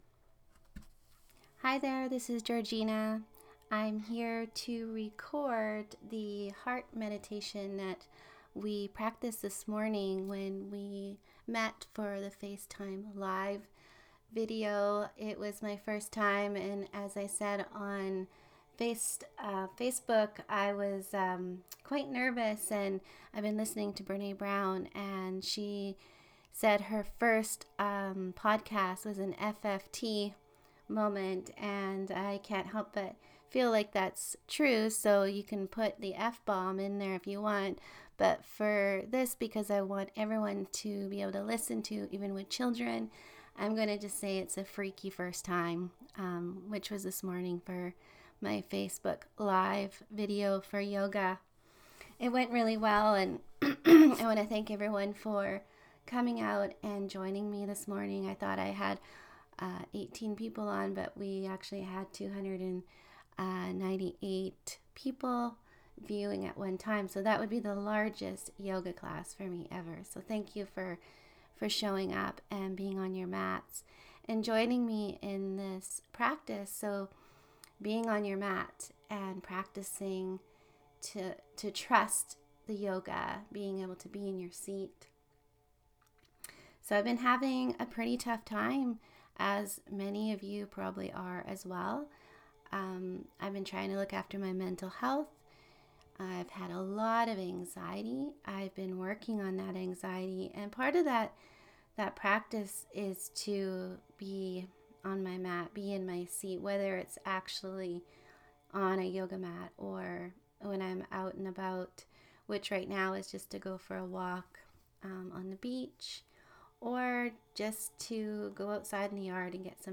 A meditation for uncertain times where we look at the word HEART. This is a relaxing mediation to bring in peace and ease while we experience challenging times.